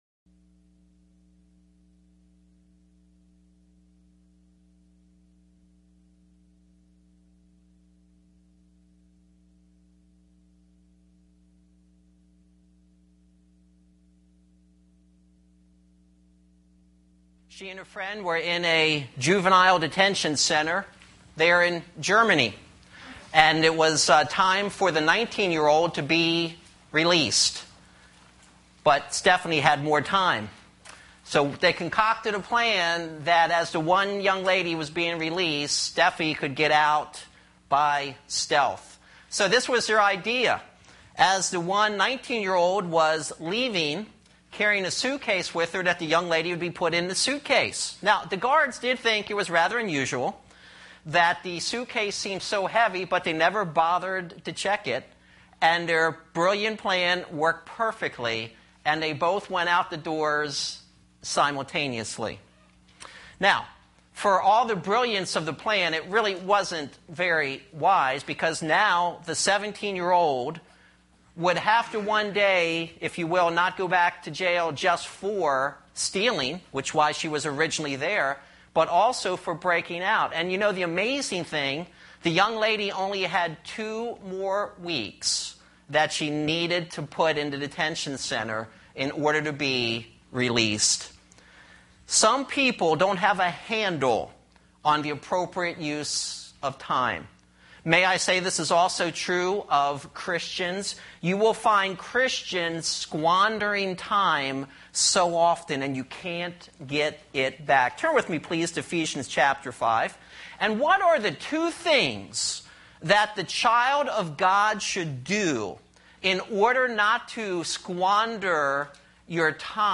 This sermon was preached at Colmar Manor Bible Church